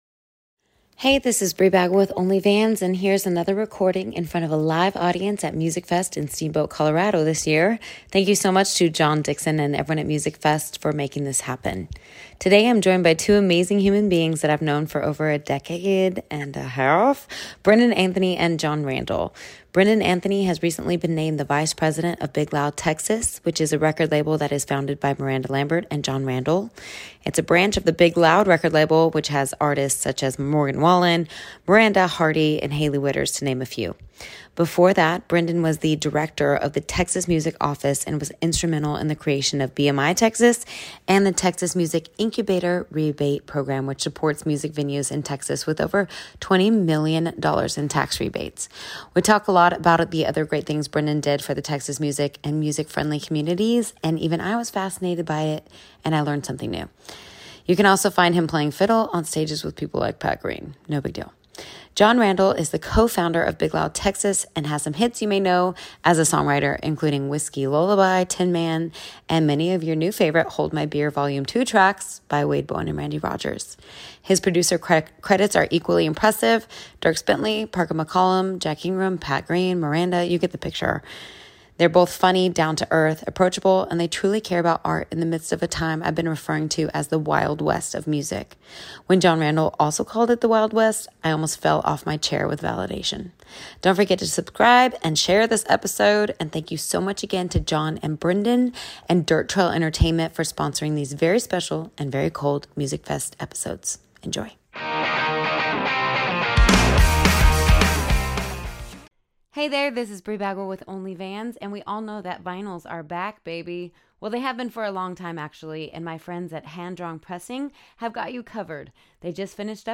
LISTEN: APPLE • SPOTIFY • AMAZON • MP3 Here is another Only Vans recording in front of a live audience at MusicFest at Steamboat in Colorado.